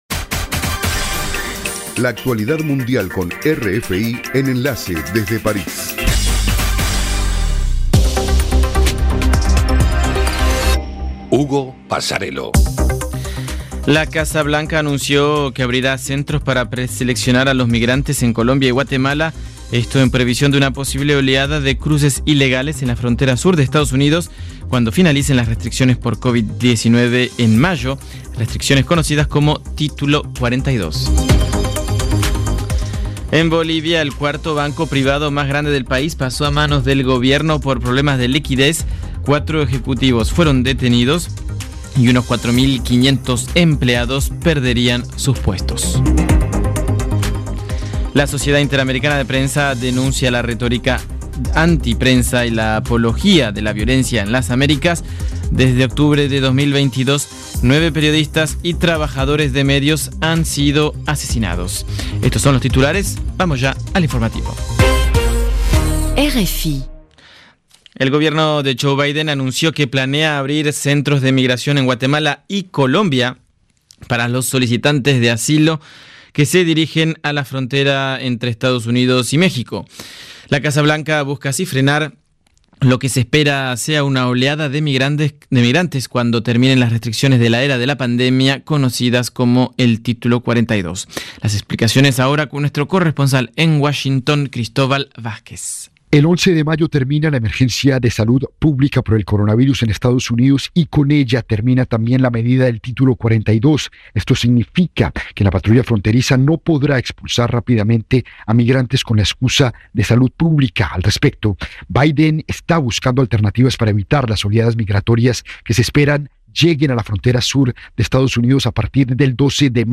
Programa: RFI - Noticiero de las 20:00 Hs.